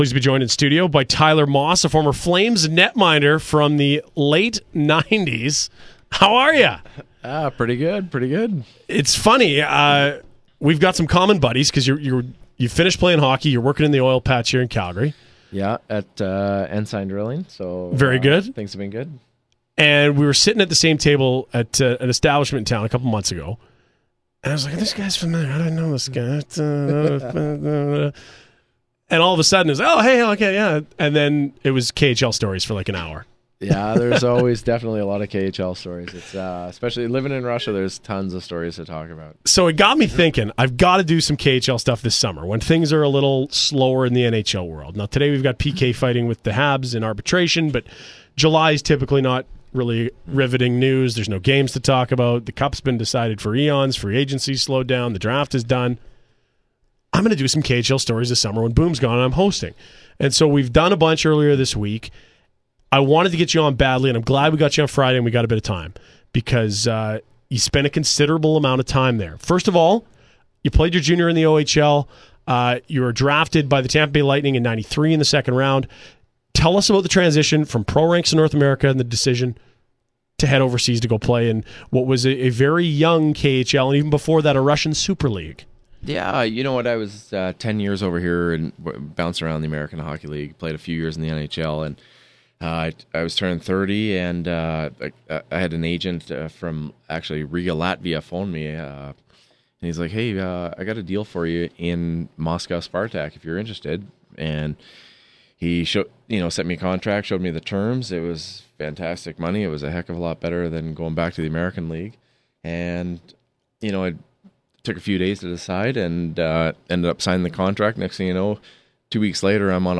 a bit of insight into Russia politics, how money moves, and a little bit of insight into why Russian players are like what they are ("the practise player")... one of the better interviews I've heard from a while...